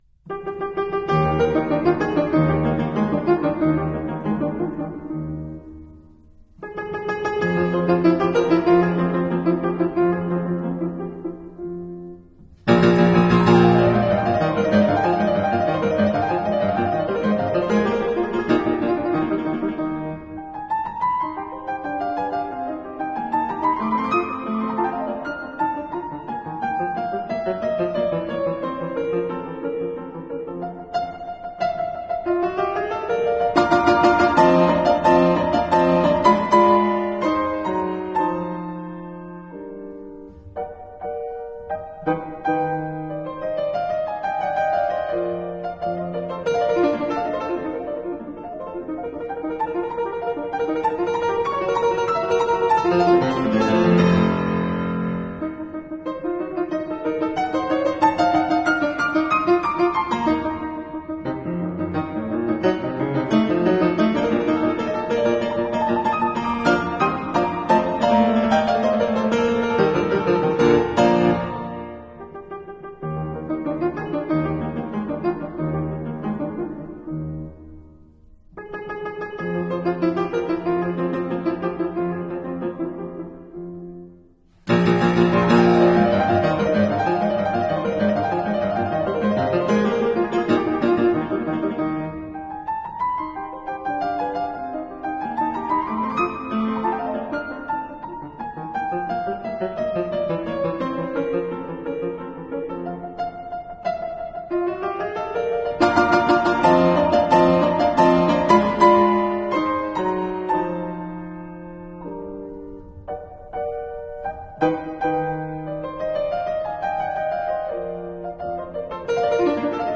Today there is a Symphony, a Piano Sonata, and a Piano Trio – the first a little sad, the others extremely warm and humorous.